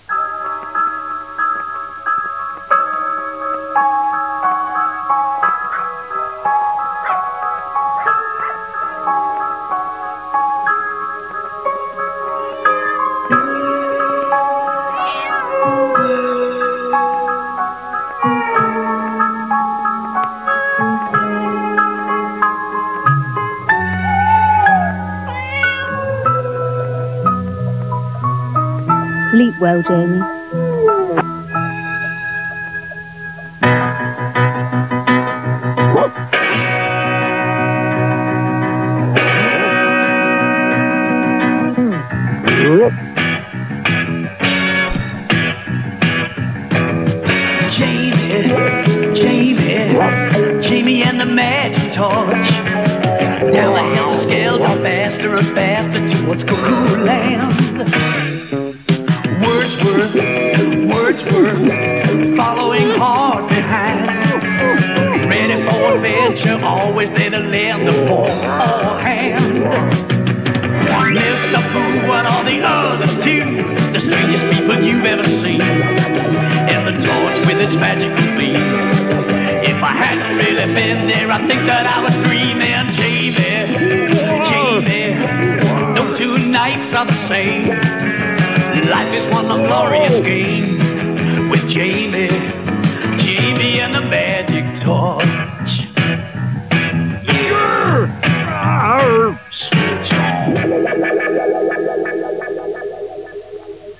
itt a zenéje isKép